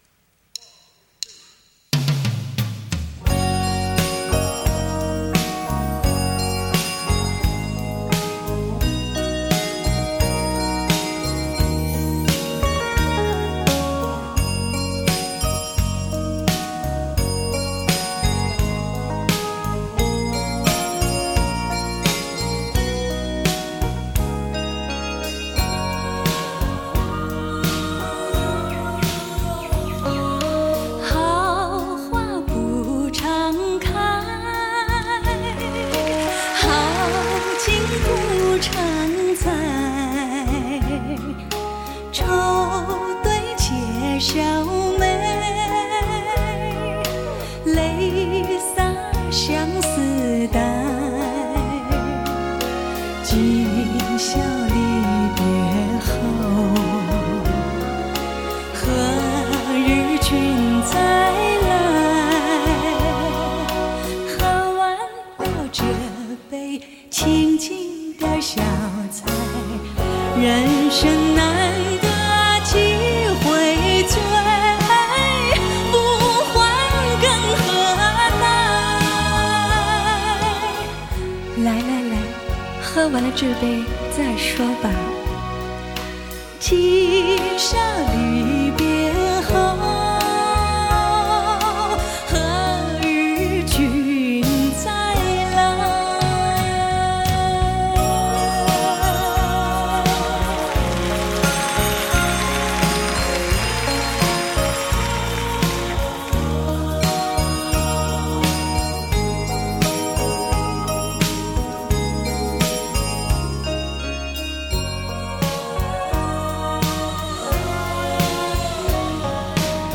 这场演唱会的录音水准，可以说是当年的最高标准，甚至超越了许多现在发行的现场录音质量。
而伴奏、和音、观众的现场声音也都独立录制。
乐队的配器也极其出色，充分展示了日本流行音乐的高超水平。